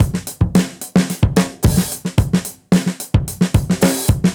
Index of /musicradar/dusty-funk-samples/Beats/110bpm
DF_BeatC_110-02.wav